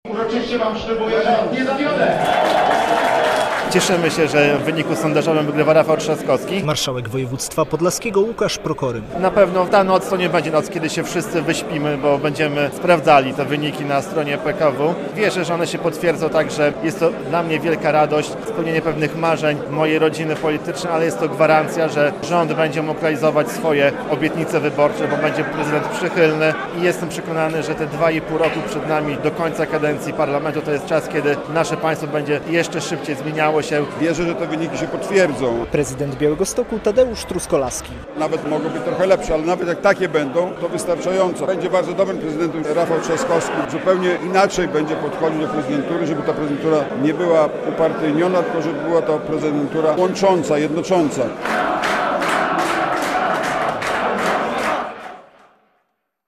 Radość i nadzieja w sztabie KO - relacja